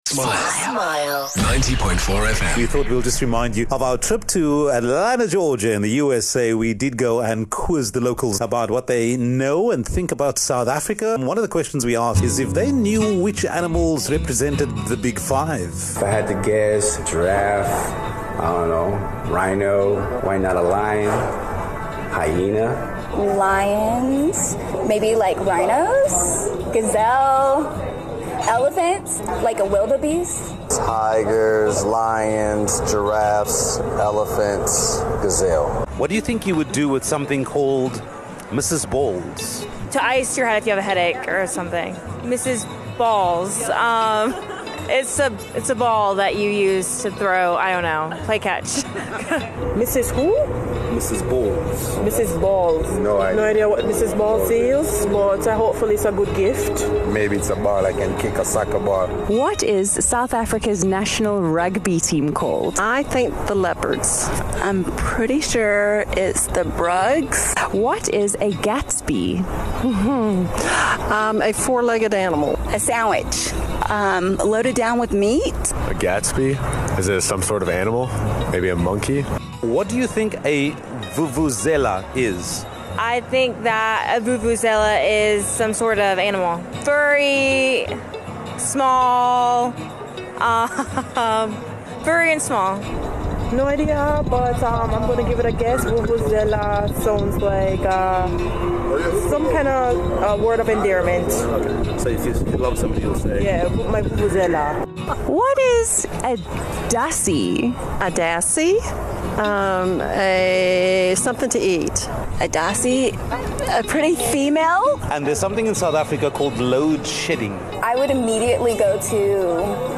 On their trip to Atlanta, U.S.A. the Smile Breakfast team took some time to get to know the locals. In trying to find out what the locals know about South Africa they asked a few questions that maybe only South Africans will understand.